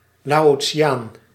Ääntäminen
Synonyymit Lao Ääntäminen US Tuntematon aksentti: IPA : /leɪˈoʊʃən/ Haettu sana löytyi näillä lähdekielillä: englanti Käännös Ääninäyte Adjektiivit 1.